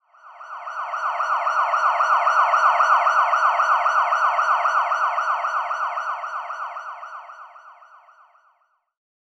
Roland.Juno.D _ Limited Edition _ GM2 SFX Kit _ 16.wav